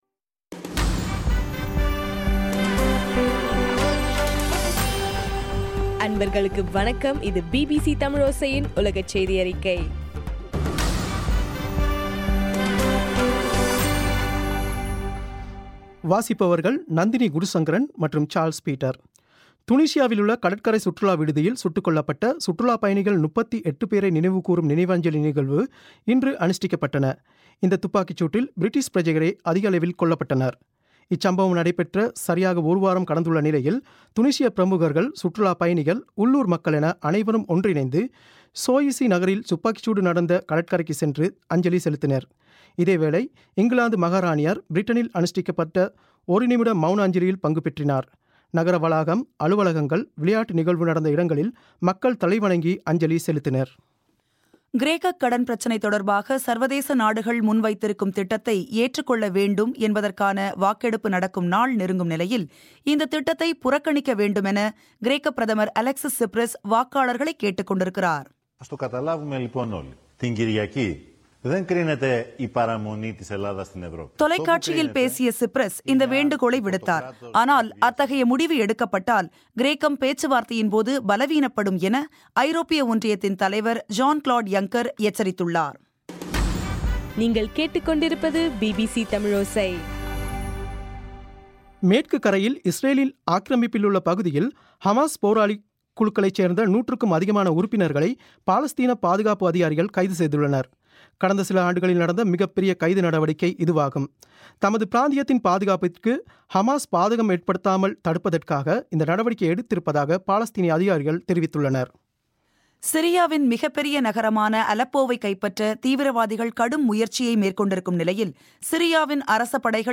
ஜூலை 3 2015 பிபிசி தமிழோசையின் உலகச் செய்திகள்